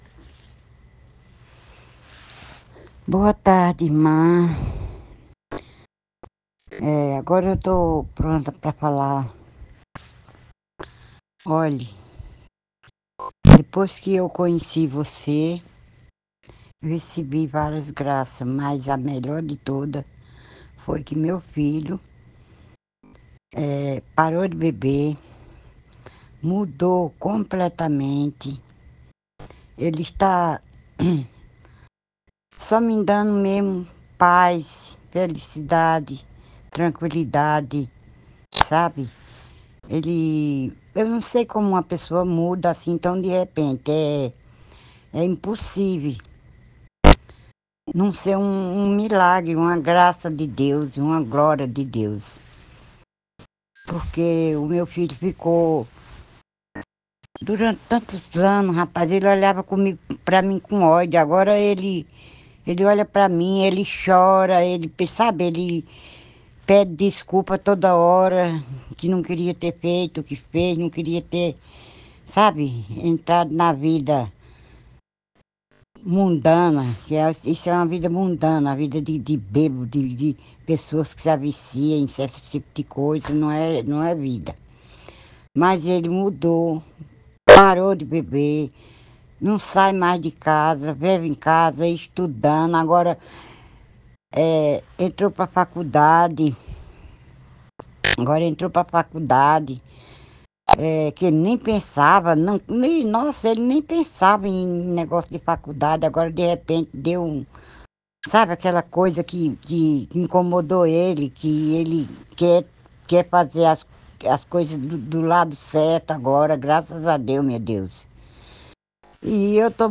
Testemunho do Espírito